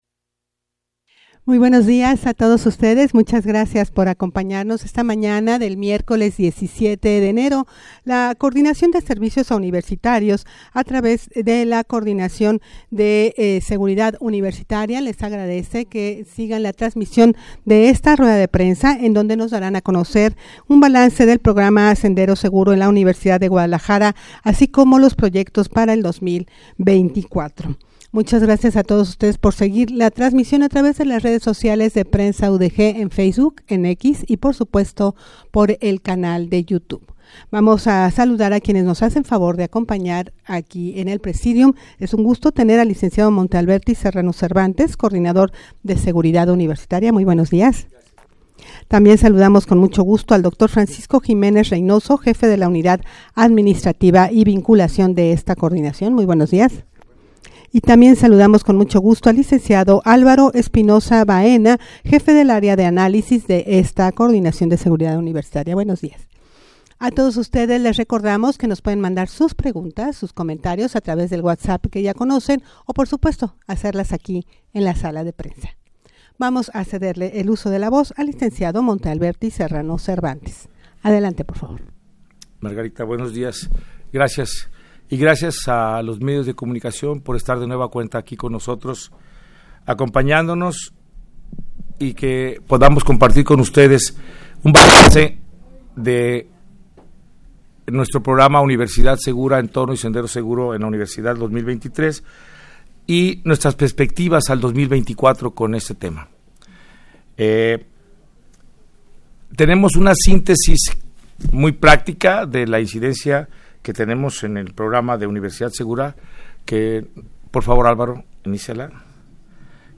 rueda-de-prensa-balance-del-programa-sendero-seguro-en-la-udeg-proyectos-para-2024.mp3